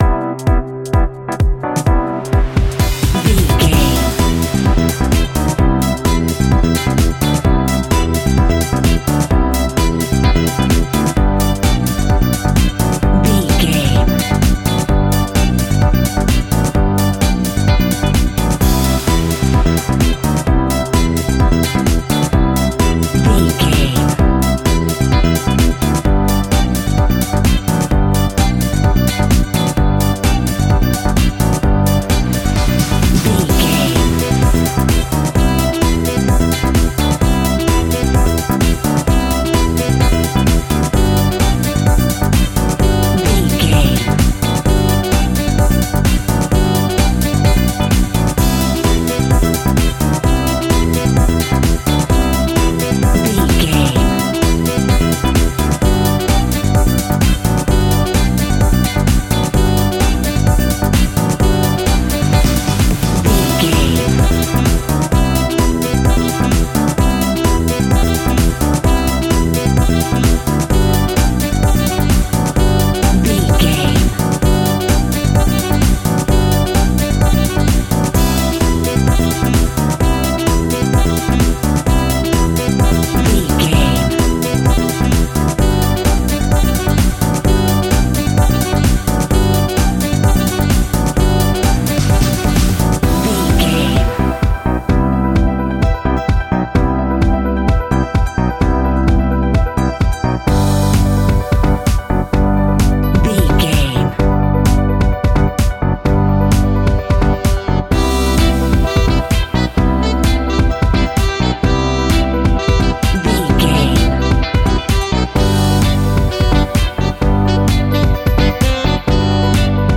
A great piece of royalty free music
Ionian/Major
groovy
uplifting
bouncy
electric guitar
bass guitar
drums
synthesiser
saxophone
disco
upbeat
clavinet